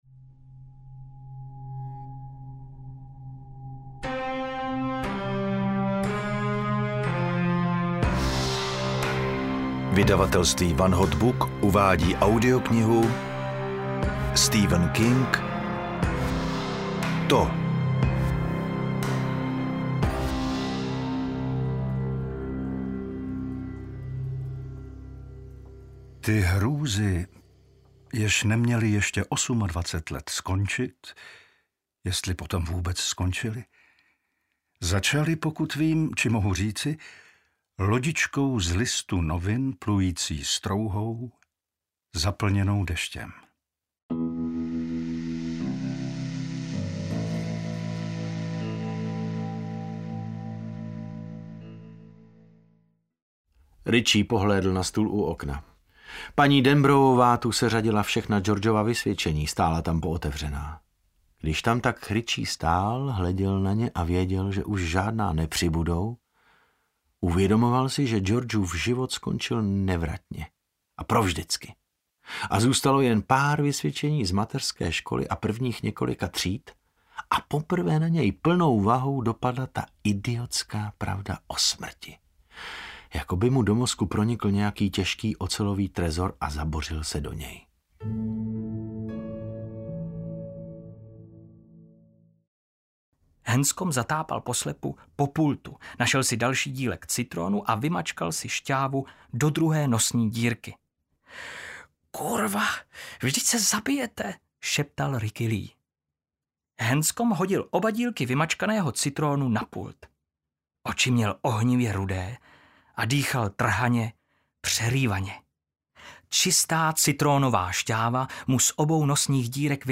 TO audiokniha
Ukázka z knihy